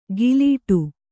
♪ gilītu